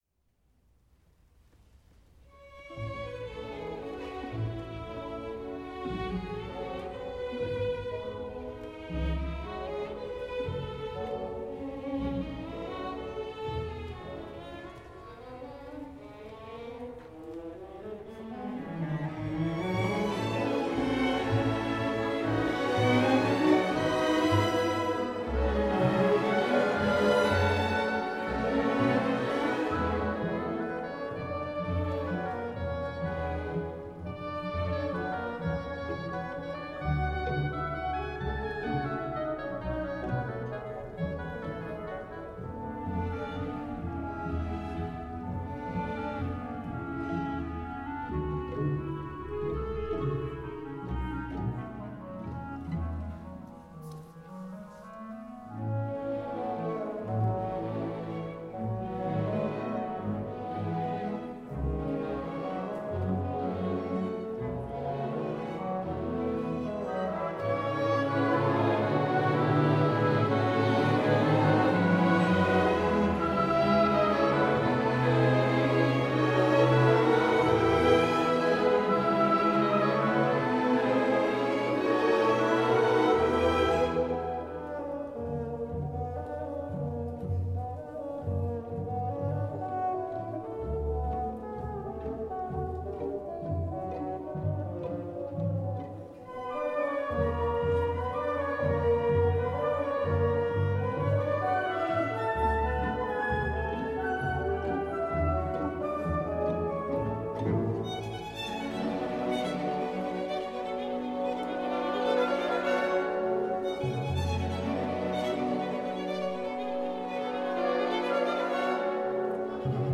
Enregistrements du concert du 24 juin 2016 à l’Oratoire du Louvre :